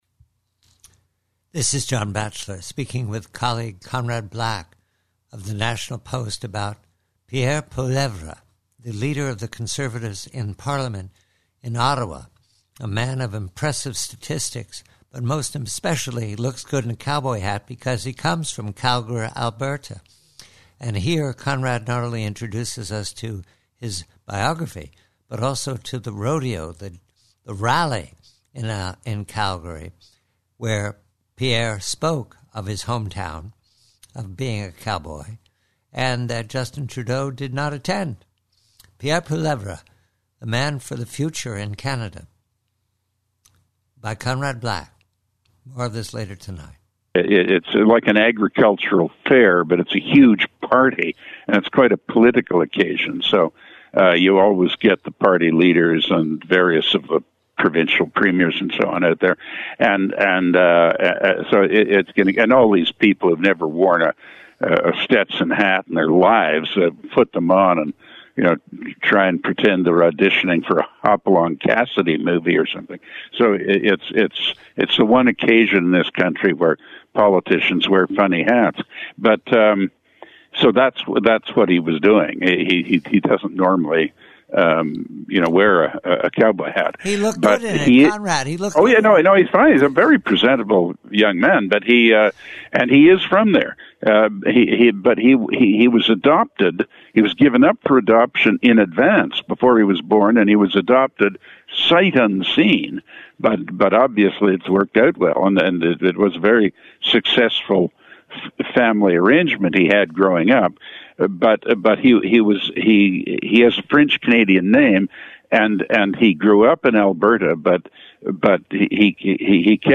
PREVIEW: CANADA: Conversation with colleague Conrad Black re: Conservative Leader Pierre Poilievre in a cowboy hat in his hometown, Calgary, Alberta.